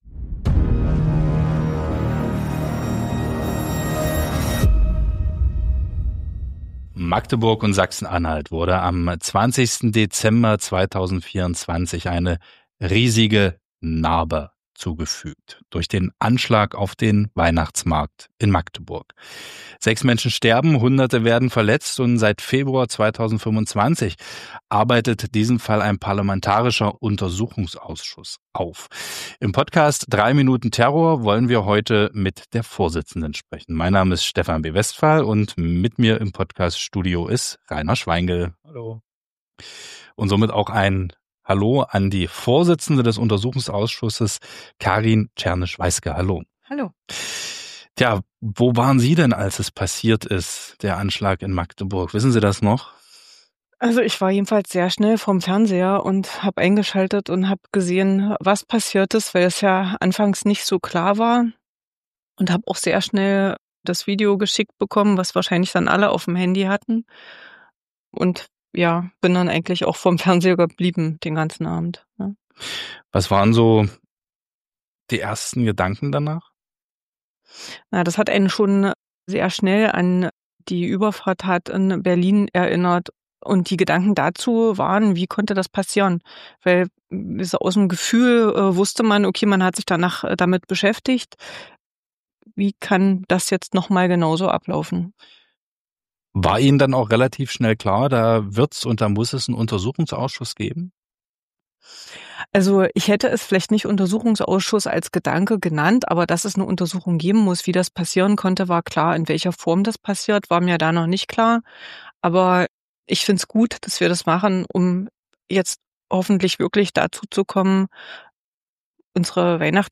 Ein eindringliches Gespräch über Aufklärung, Verantwortung und die Frage, wie Gesellschaft und Politik gemeinsam für mehr Sicherheit sorgen können.